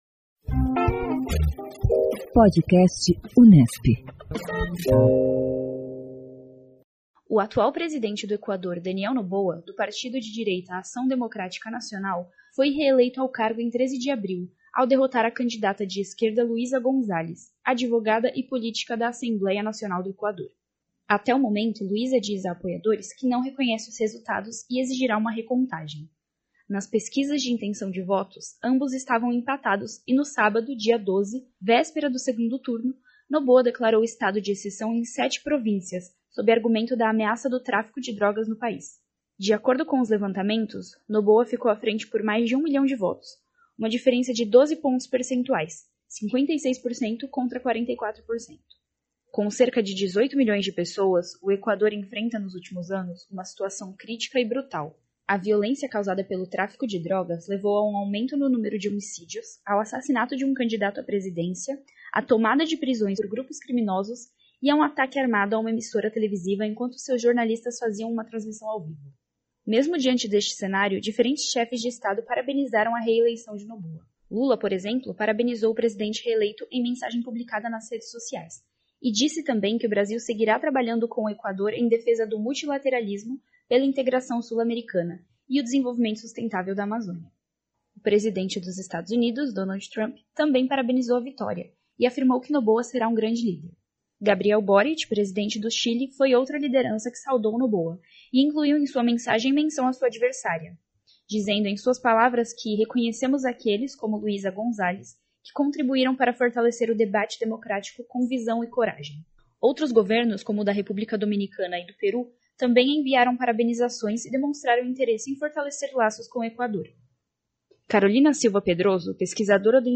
O Podcast Unesp divulga semanalmente entrevistas com cientistas políticos sobre as mais variadas pautas que englobam o universo político e as relações internacionais.